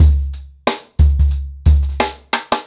TrackBack-90BPM.57.wav